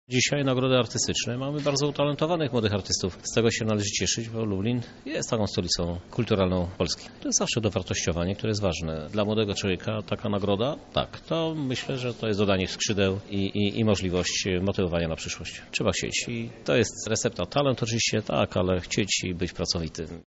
W Lublinie mamy wielu uzdolnionych, młodych ludzi, którzy mają swój wkład w tworzenie kultury – mówi Prezydent Miasta Lublin, Krzysztof Żuk: